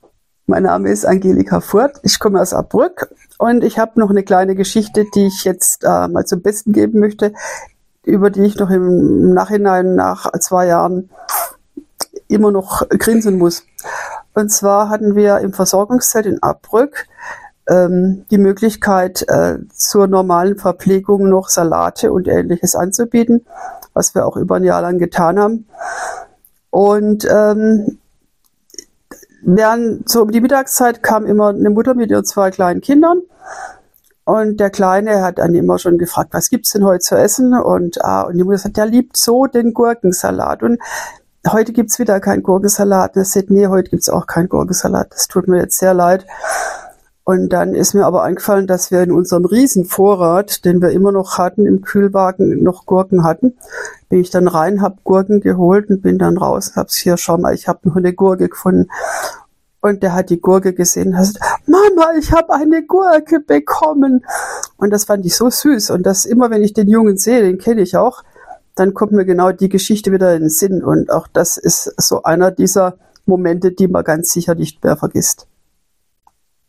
Bei den Aufnahmen, die oftmals in den Räumen der Waschbar stattfanden, ging es nicht um Perfektion, sondern um das wirkliche Leben. So begleiten schon mal Kirchenglocken oder auch Baustellengeräusche das gesprochene Wort.